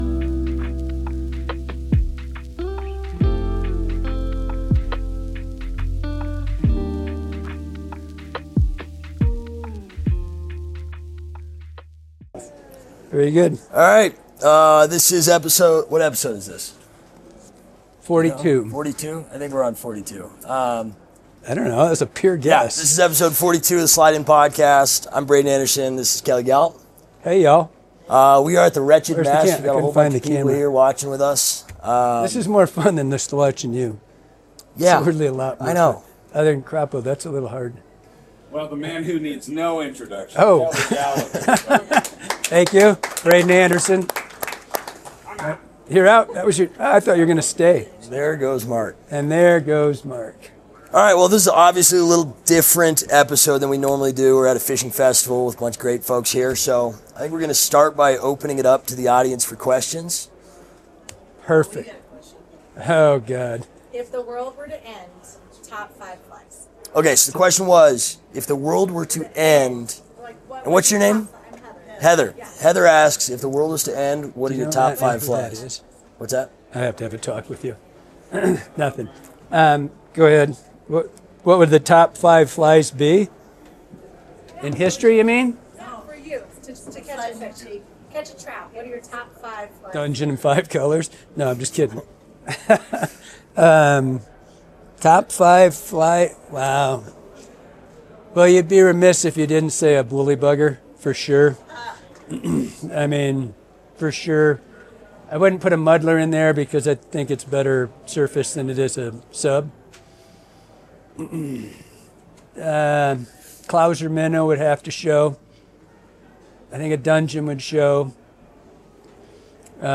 The Slide Inn Podcast Ep. 42: LIVE From The Wretched Mess Festival